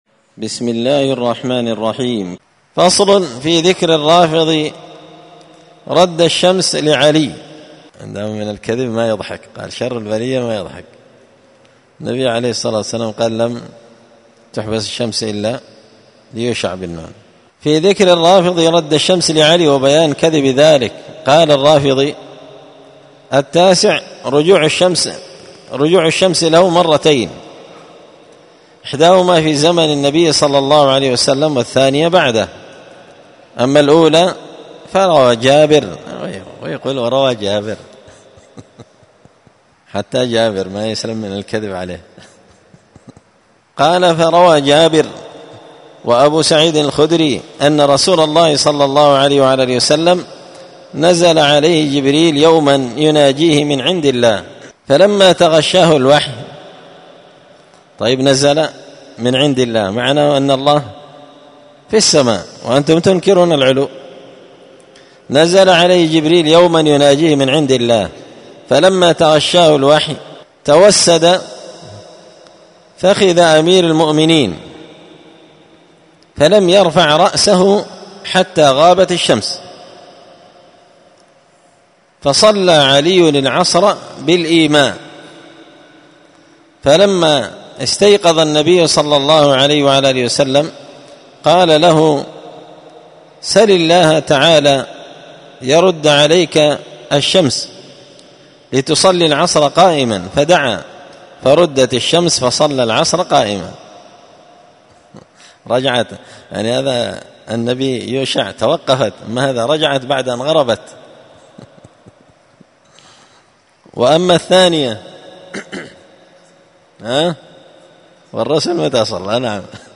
الخميس 6 ربيع الأول 1445 هــــ | الدروس، دروس الردود، مختصر منهاج السنة النبوية لشيخ الإسلام ابن تيمية | شارك بتعليقك | 65 المشاهدات
مسجد الفرقان قشن_المهرة_اليمن